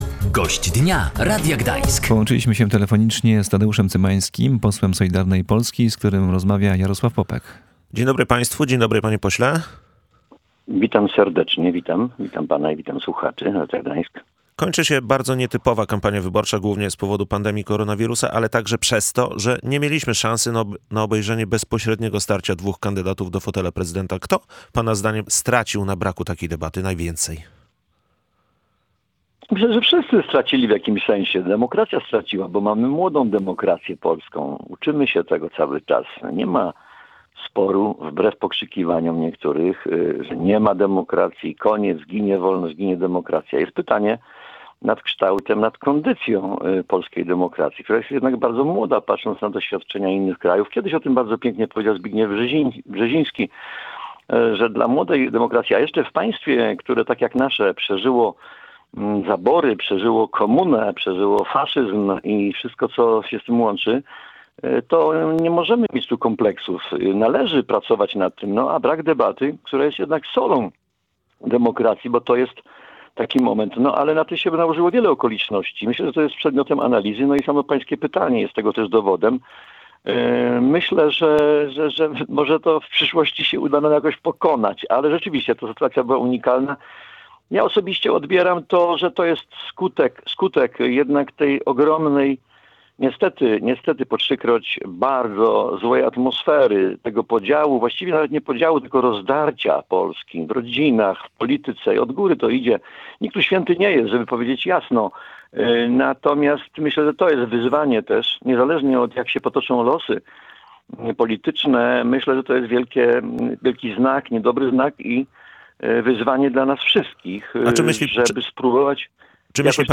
Kto najwięcej stracił na braku debaty między kandydatami? Co jest stawką tych wyborów? Między innymi o tym rozmawialiśmy w audycji Gość Dnia Radia Gdańsk.